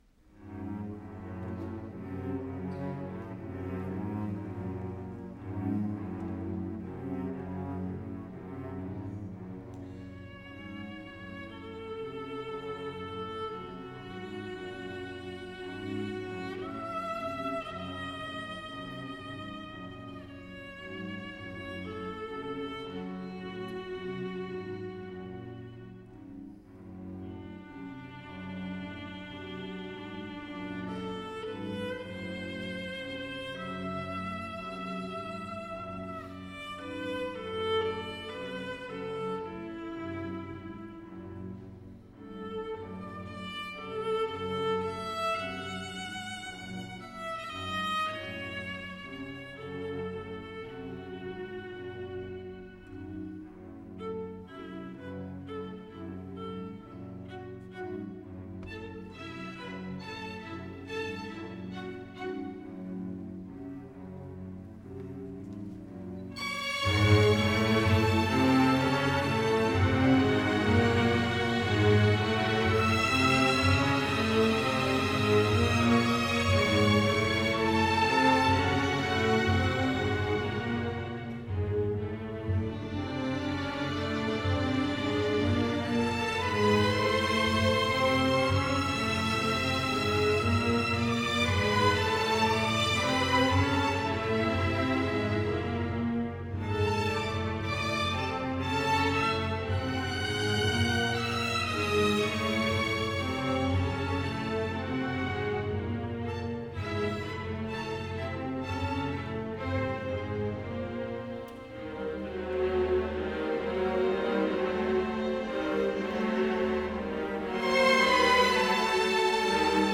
Veress: Four Transylvanian Dances Nr. 3 – Letjós (Andantino)